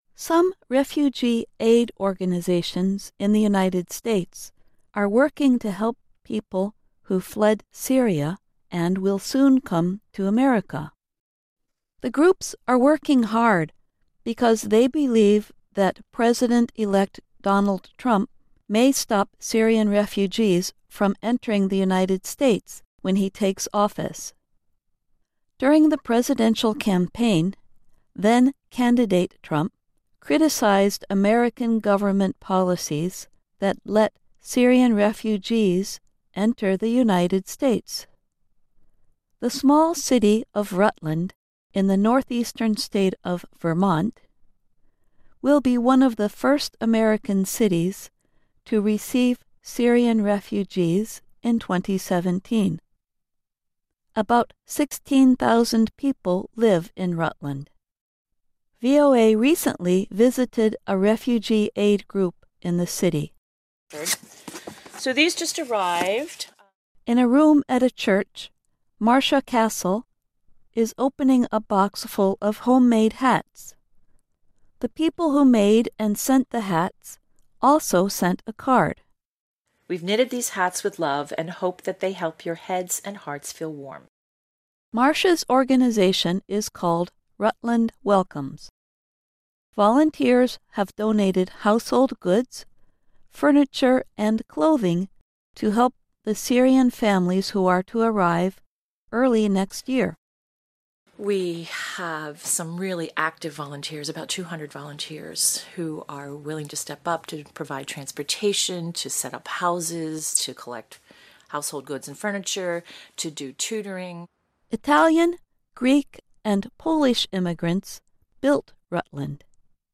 reported this story from Rutland, Vermont